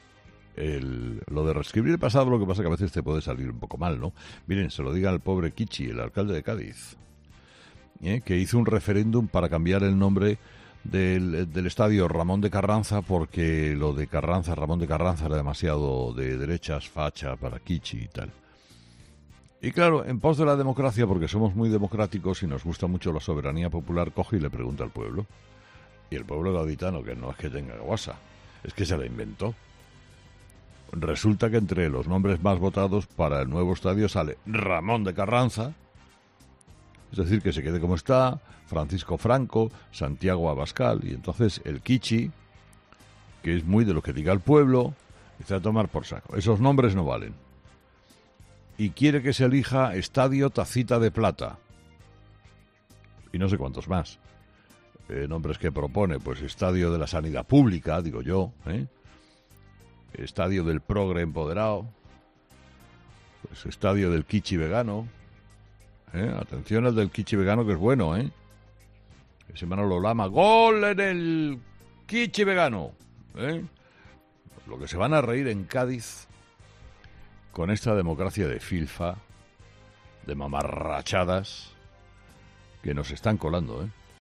En su monólogo de este jueves, Carlos Herrera ha tirado de ironía para tratar este asunto.